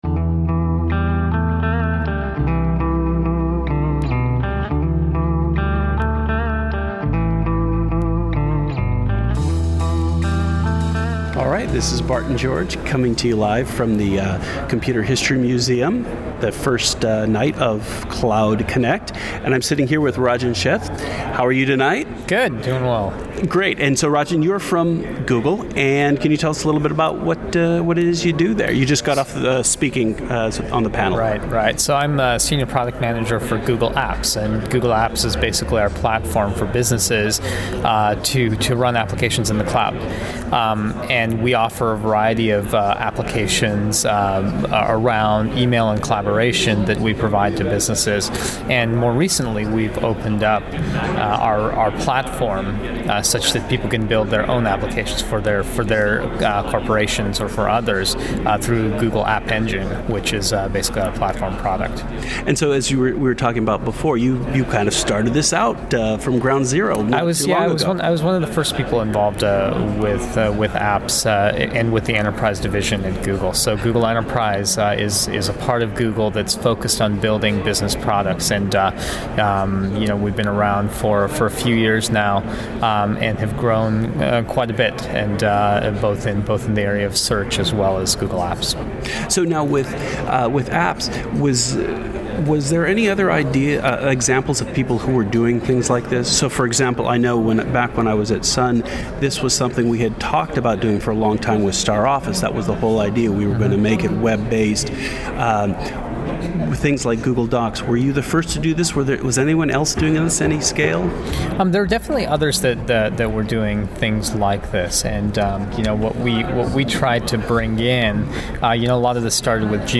The week before last, the Cloud Connect event in Mountain View kicked off with an “Evening in the Cloud.”